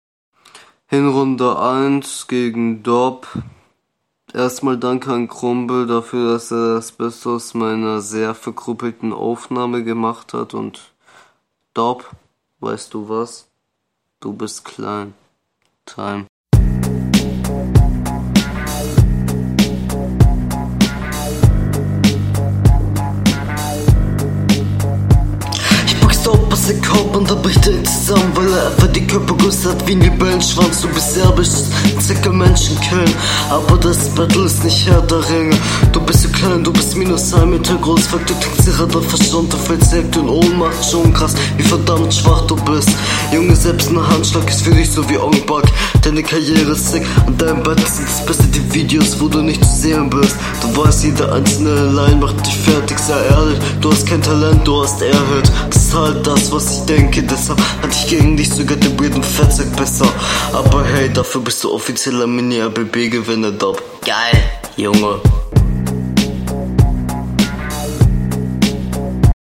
Flow: joa ist nicht scheiße aber halt sehr verhaspelt darauf solltest du achten man versteht …
Flow: Flow ist so ganz ok aber sehr oft offbeat Text: kann man meistens nichts …
Flow: Naja du triffst einfach nicht immer den Takt hört sich dann nicht so gut …